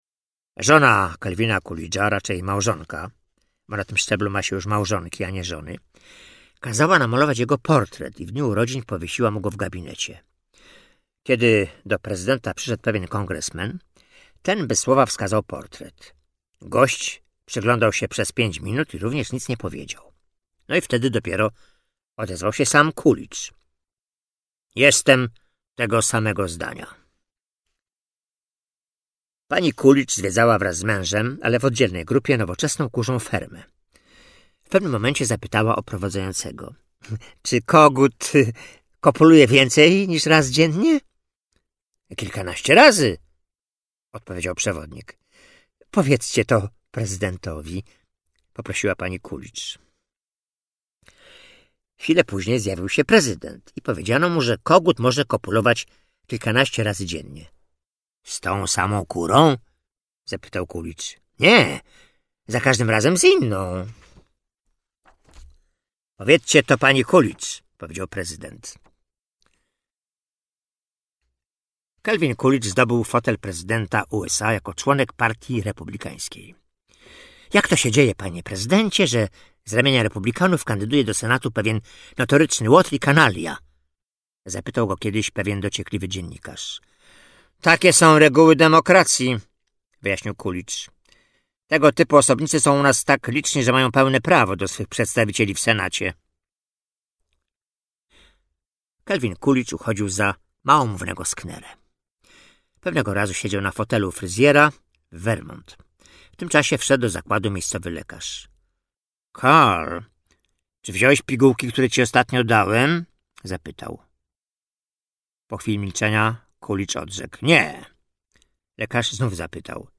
Najlepsze anegdoty o politykach - Audiobook mp3